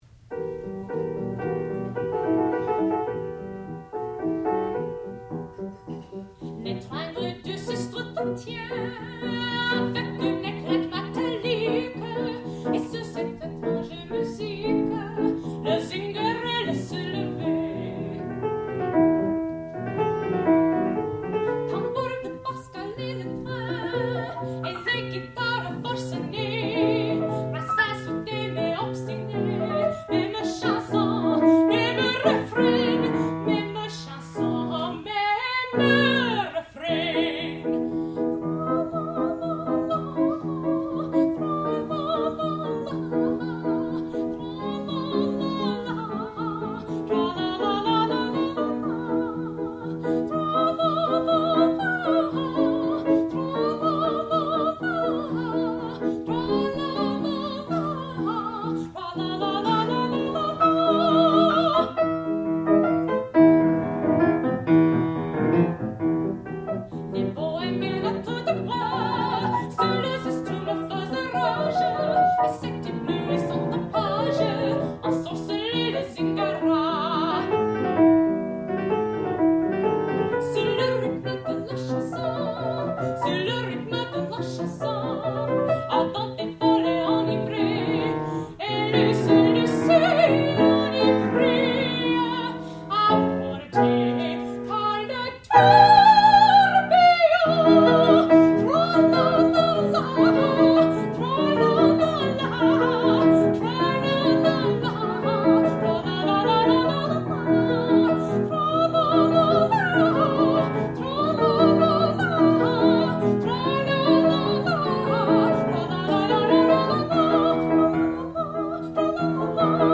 sound recording-musical
classical music
Qualifying Recital
mezzo-soprano